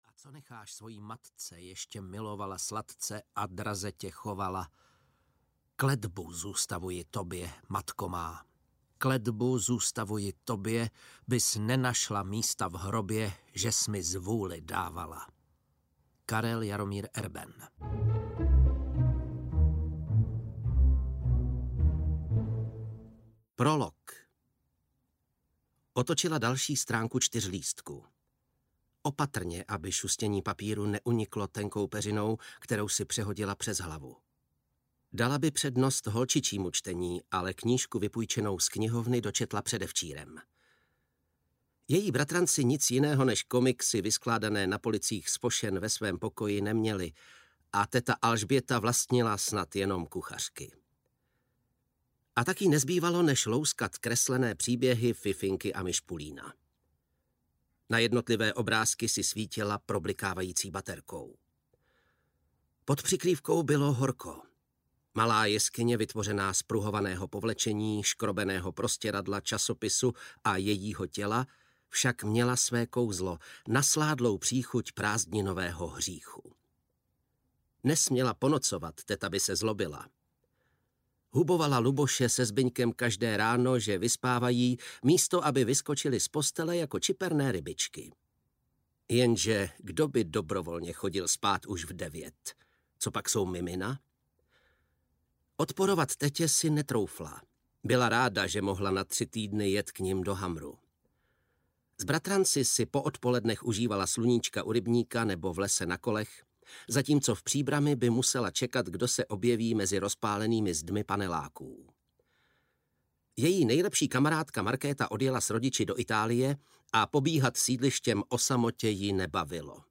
Dceřina kletba audiokniha
Ukázka z knihy